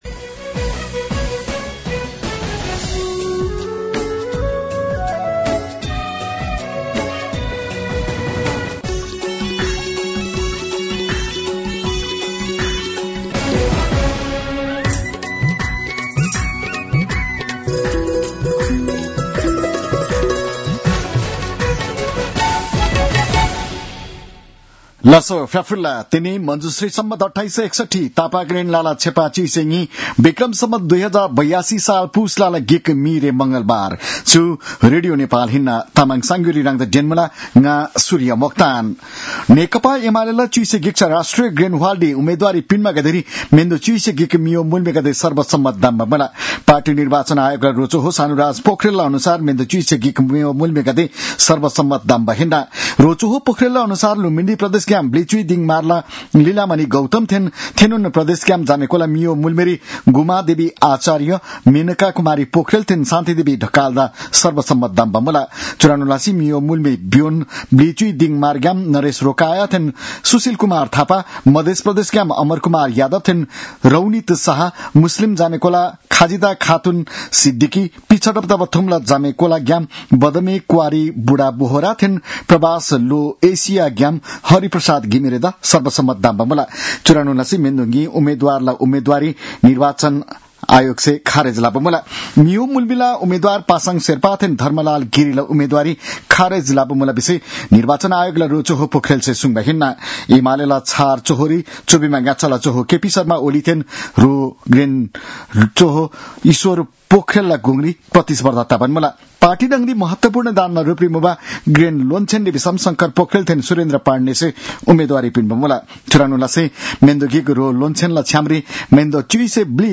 तामाङ भाषाको समाचार : १ पुष , २०८२